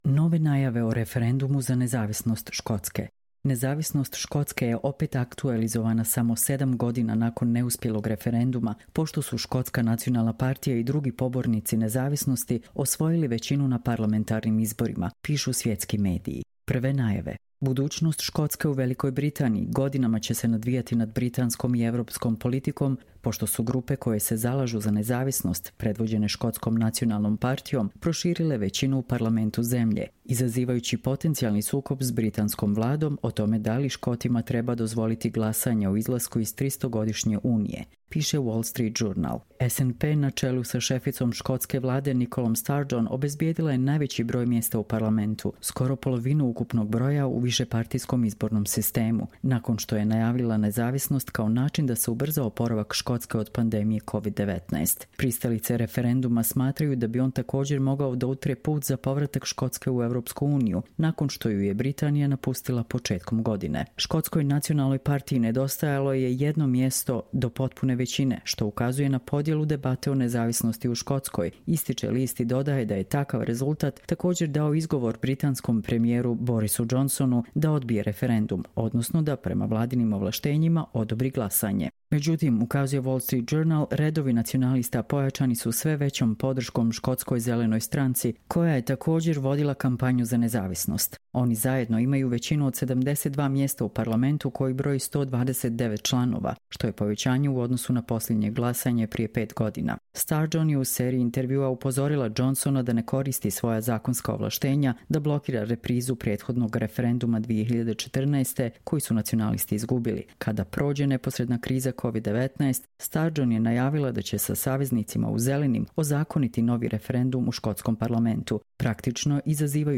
Čitamo vam: Nove najave o referendumu za nezavisnost Škotske